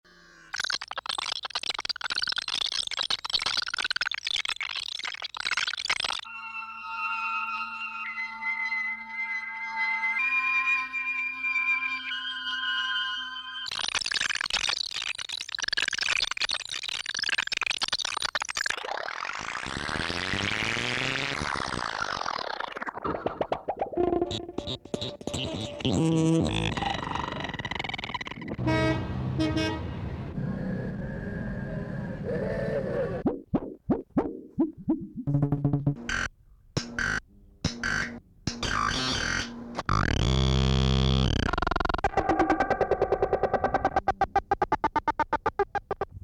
musique concrète